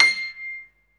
SG1 PNO  C 6.wav